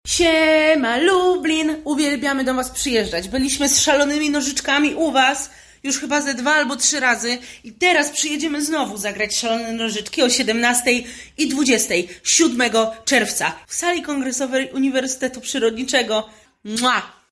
mówi Dominika Gwit, odtwórczyni jednej z ról.